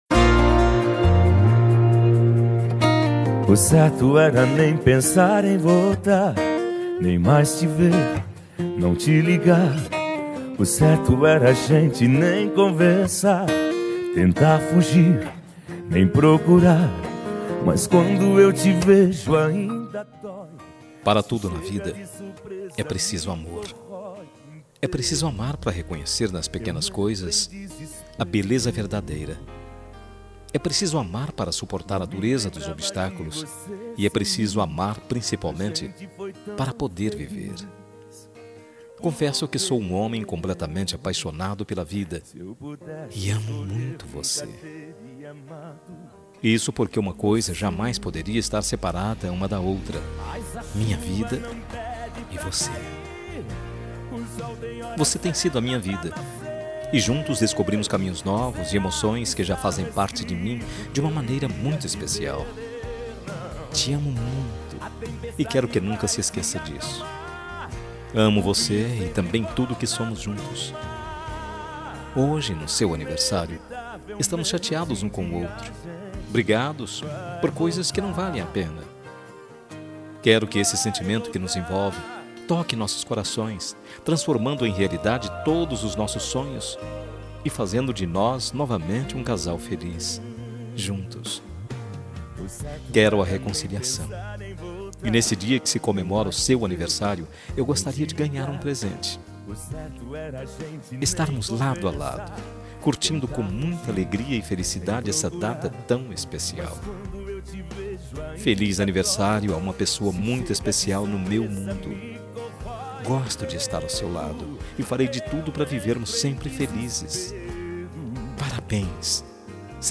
Voz Masculina